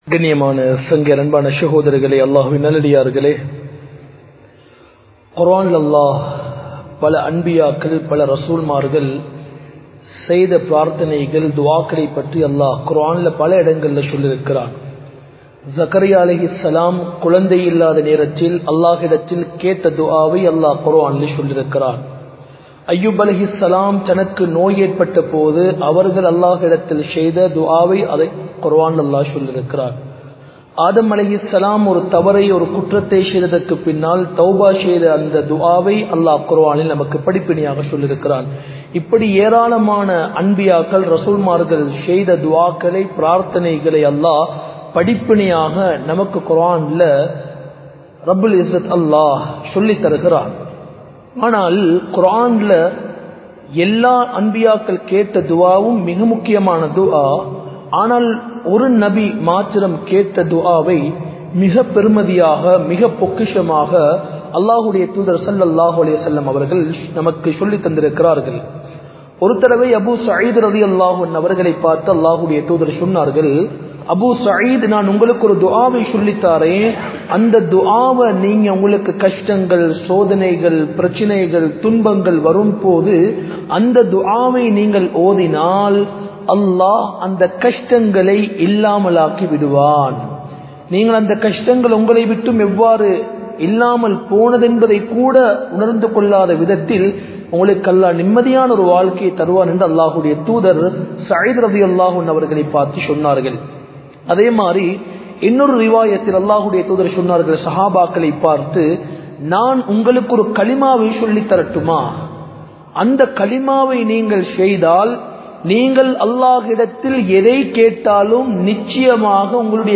Bayans
Colombo 04, Majma Ul Khairah Jumua Masjith (Nimal Road)